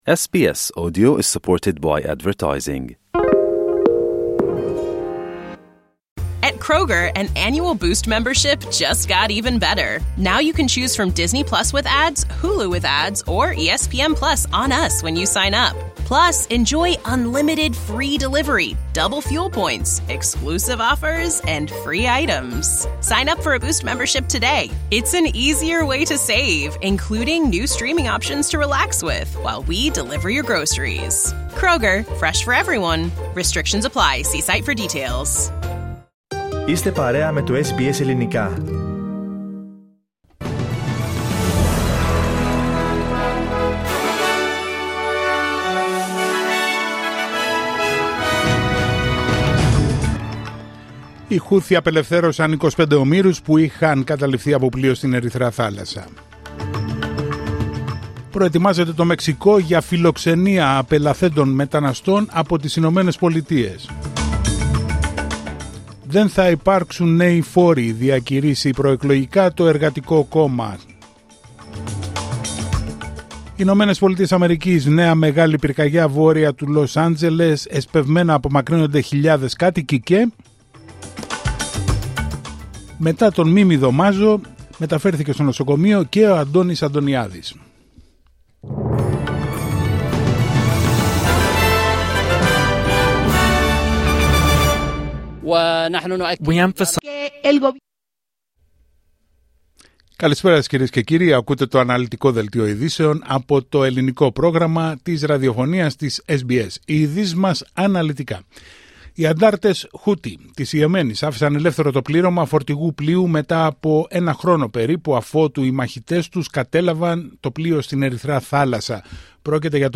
Δελτίο ειδήσεων Πέμπτη 23 Ιανουαρίου 2025